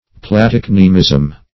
platycnemism - definition of platycnemism - synonyms, pronunciation, spelling from Free Dictionary
Platycnemism \Pla*tyc"ne*mism\, n.